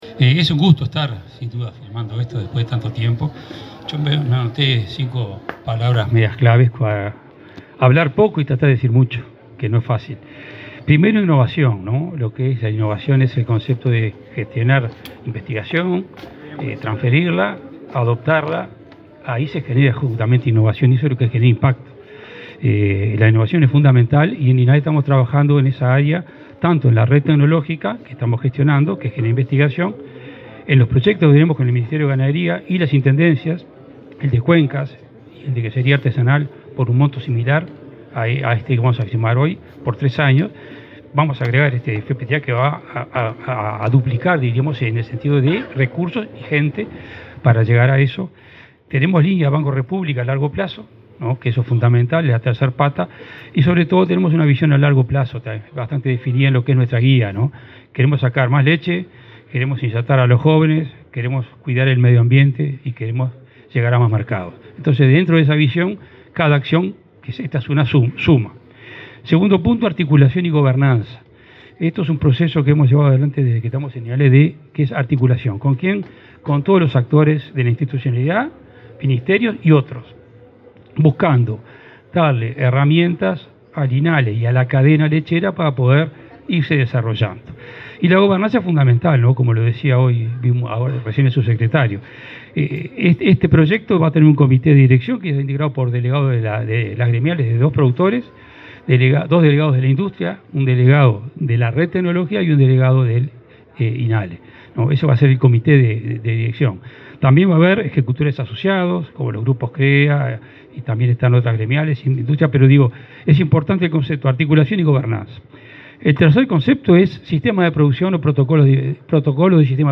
Palabras del titular del Inale, Juan Daniel Vago
Palabras del titular del Inale, Juan Daniel Vago 14/09/2023 Compartir Facebook X Copiar enlace WhatsApp LinkedIn Este jueves 14 en la Expo Prado, el titular del Instituto Nacional de Investigación Agropecuaria (INIA), José Bonica, y su par del Instituto Nacional de la Leche (Inale), Juan Daniel Vago, suscribieron un convenio en el marco del Fondo de Promoción de Tecnología Agropecuaria.